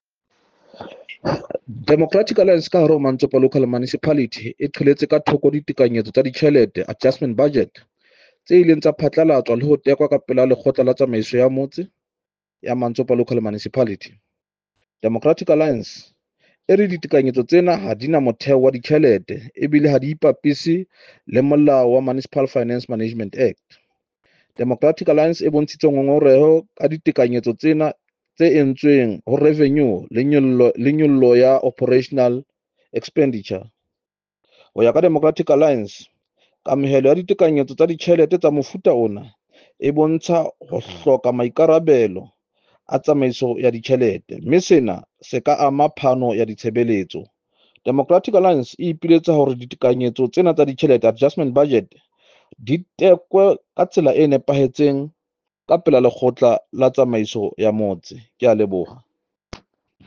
Sesotho soundbite by Cllr Tim Mpakathe.